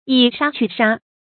以殺去殺 注音： ㄧˇ ㄕㄚ ㄑㄩˋ ㄕㄚ 讀音讀法： 意思解釋： 用嚴峻的法律禁止人犯法。